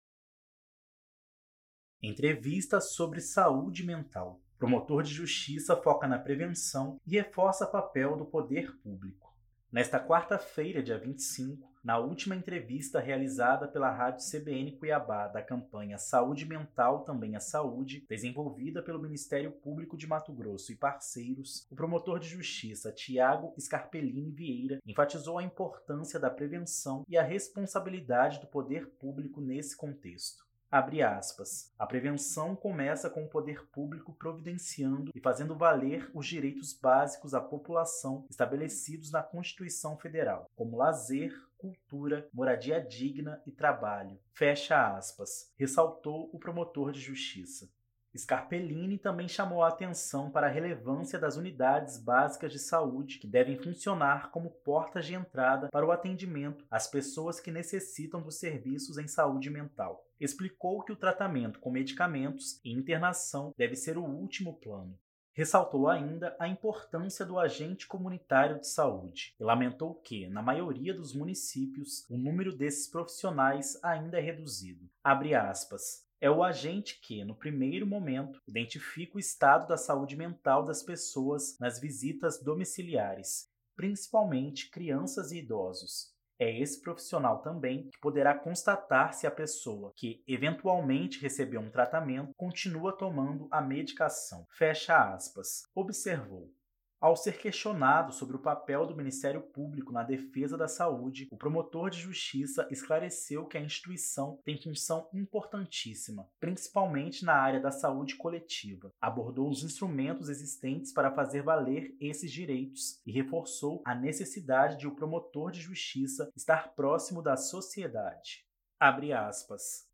ENTREVISTA SOBRE SAÚDE MENTAL
Nesta quarta-feira (25), na última entrevista realizada pela Rádio CBN Cuiabá da campanha “Saúde Mental também é Saúde”, desenvolvida pelo Ministério Público do Estado de Mato Grosso e parceiros, o promotor de Justiça Thiago Scarpellini Vieira enfatizou a importância da prevenção e a responsabilidade do poder público nesse contexto.